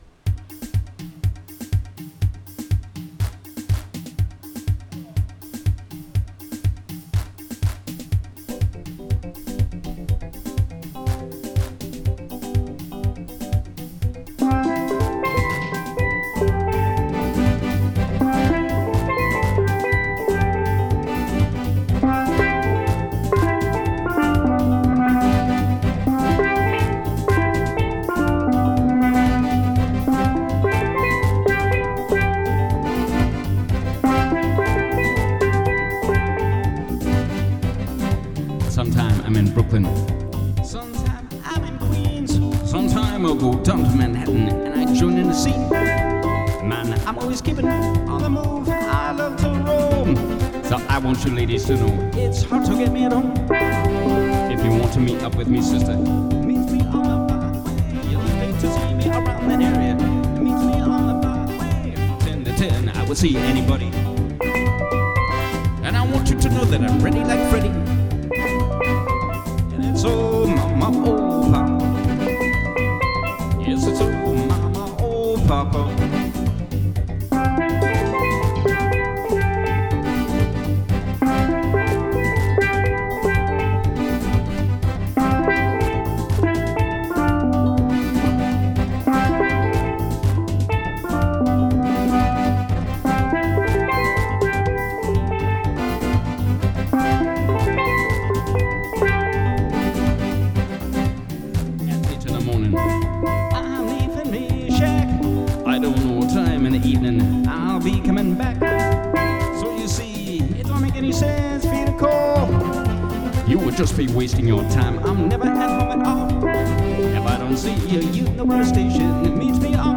Steel Drum